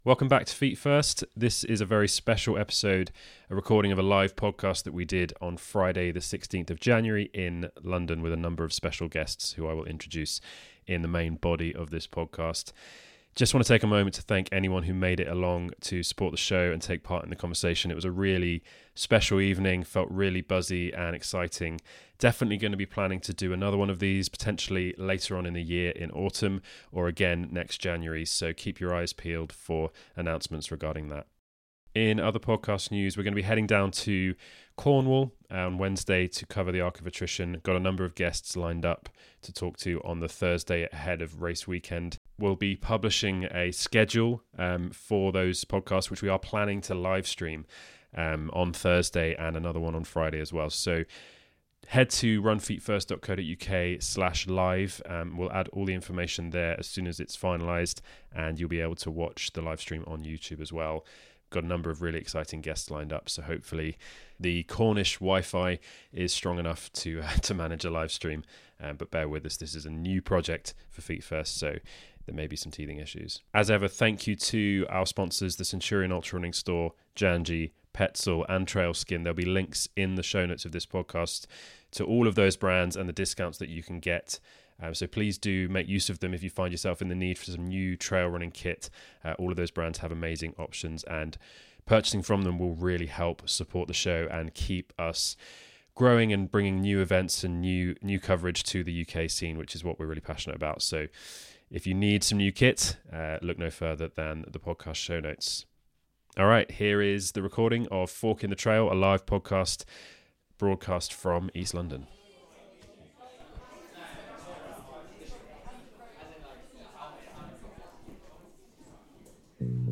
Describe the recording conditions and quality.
LIVE EPISODE: Fork in the Trail - Exploring the Past, Present, and Future of British Ultramarathon Racing Our first ever live podcast! Recorded on Friday 16th January at SALT Salon, Dalston.